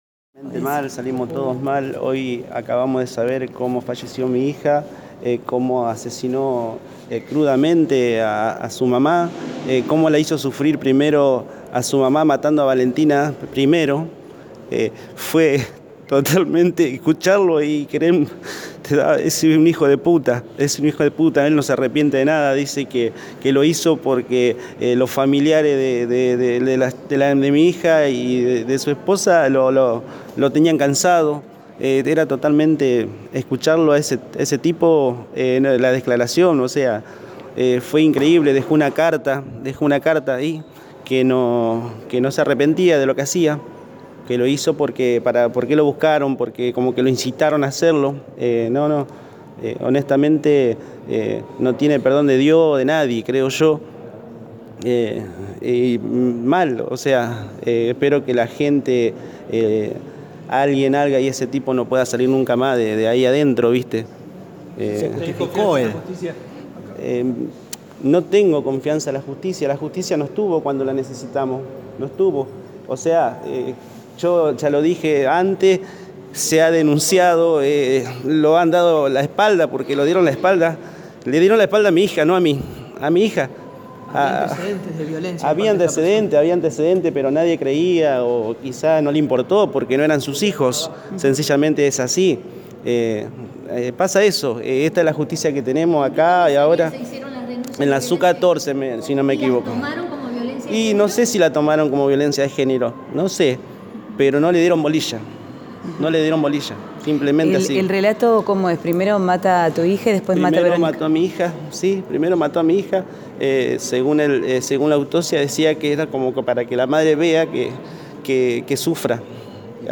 desde los Tribunales santafesinos: